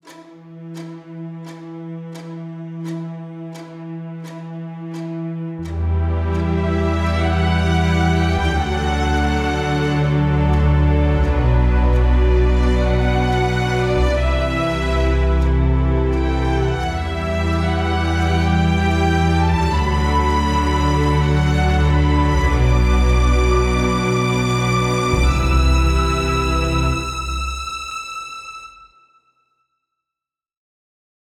Filmmusik-Cue